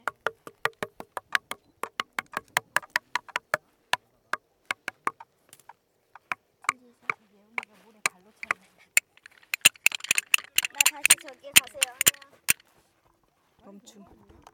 두드리는04.mp3